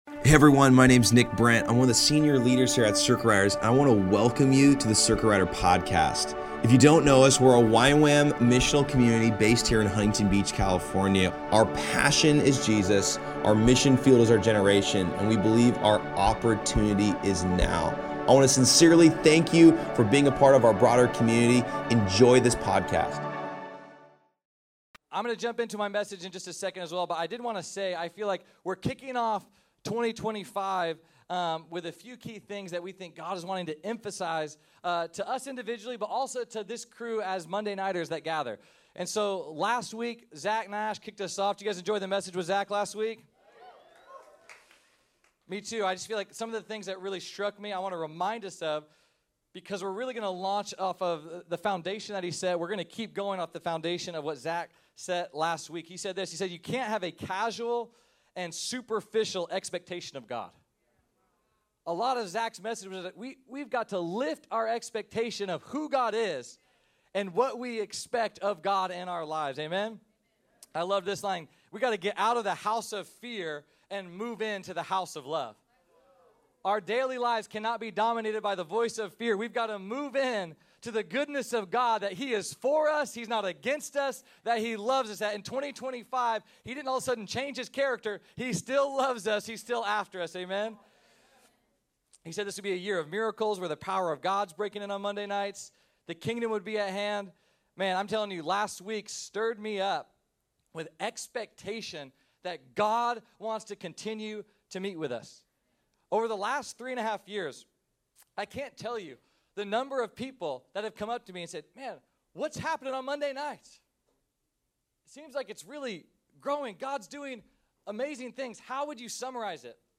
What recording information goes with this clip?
At the Circuit Riders Monday Nights gathering